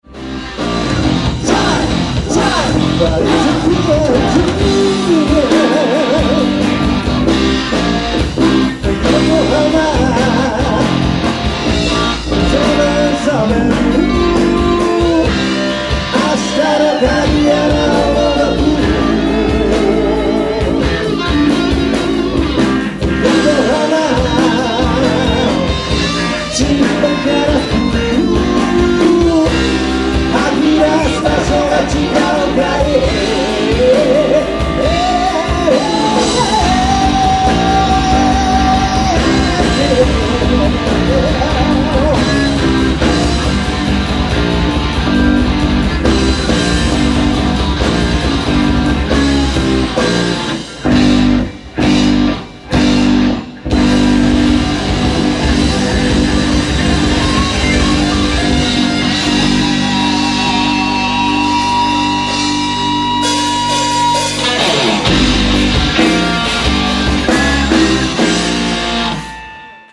30年目の最後のライブです。
guitar,keybords,chorus
bass,chorus
drums